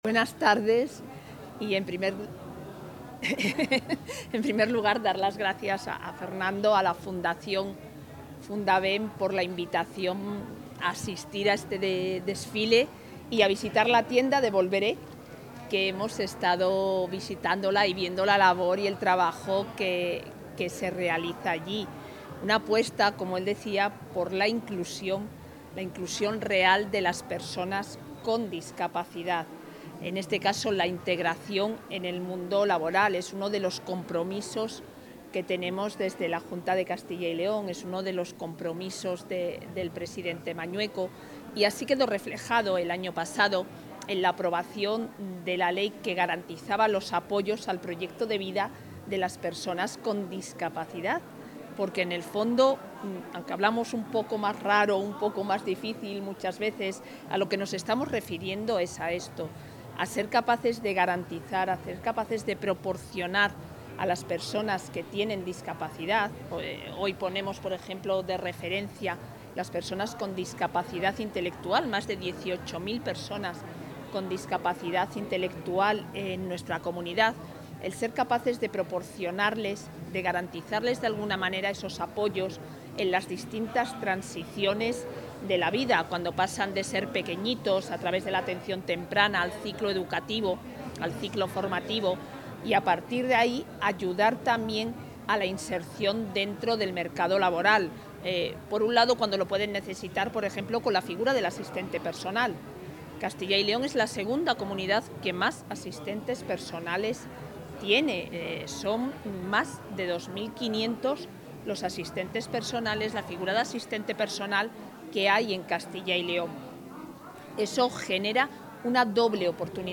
Declaraciones de la vicepresidenta.
La vicepresidenta y consejera de Familia e Igualdad de Oportunidades asiste al desfile de moda inclusivo de la entidad abulense Fundabem, protagonizado por miembros de este colectivo, quienes también se encargan de gestionar las dos tiendas de segunda mano que tiene la organización.